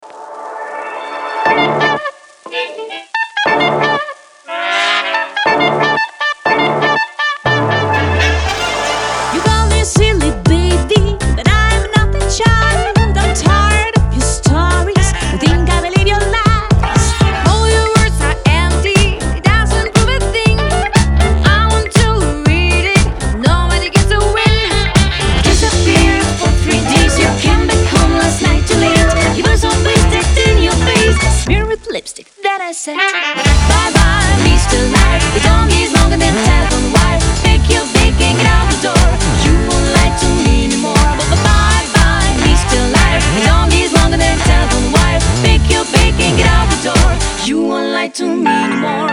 • Качество: 320, Stereo
громкие
женский вокал
веселые
заводные
инструментальные
джаз